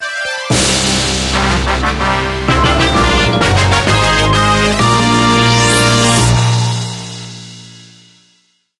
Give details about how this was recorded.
Ripped from the game applied fade-out on the last two seconds when needed.